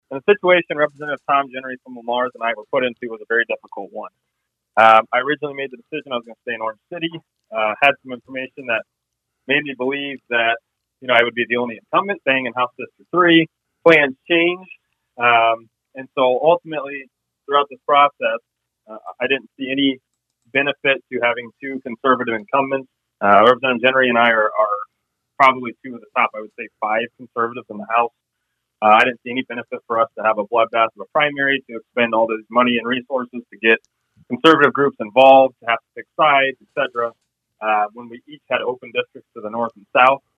Last week, Representative Jeneary announced his plans to also seek re-election in the newly-minted 3rd Legislative District, which would have forced a primary between the two Republican incumbents, putting the two friends and colleagues into a difficult situation, according to Wheeler.